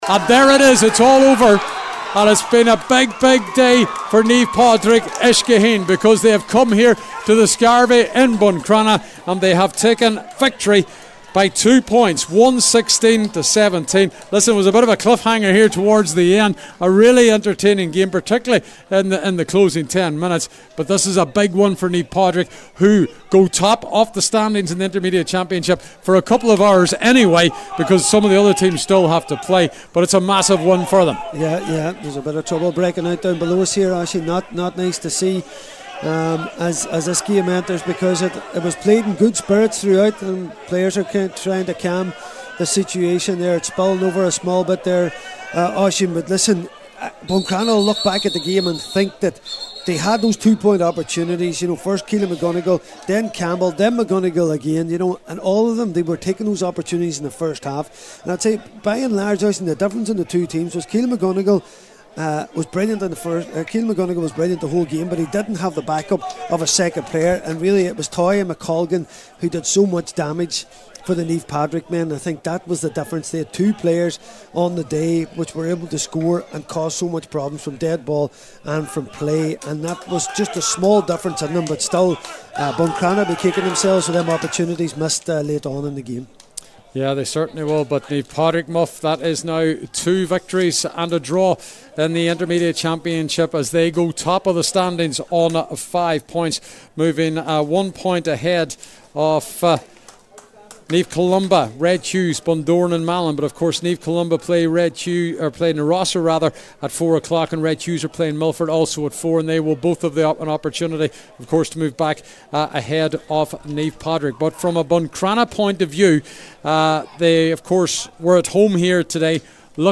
FT Report & Reaction as Naomh Padraig Uisce Chaoin pip Buncrana in Inishowen derby